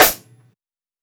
TRY_RIM.wav